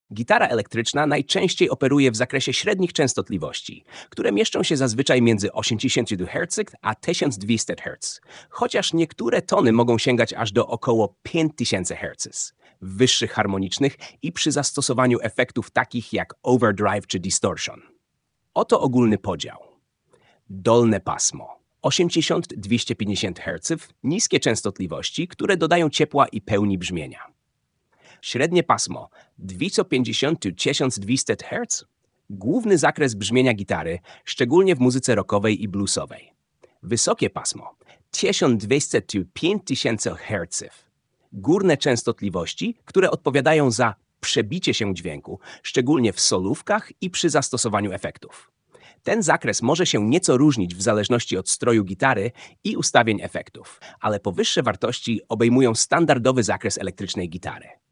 Gitara elektryczna pasmo akustyczne
Gitara elektryczna najczęściej operuje w zakresie średnich częstotliwości, które mieszczą się zazwyczaj między 80 Hz a 1200 Hz, chociaż niektóre tony mogą sięgać aż do około 5000 Hz (w wyższych harmonicznych i przy zastosowaniu efektów takich jak overdrive czy distortion).
• Dolne pasmo: 80-250 Hz – niskie częstotliwości, które dodają ciepła i pełni brzmienia.
Gitara-elektryczna-pasmo.mp3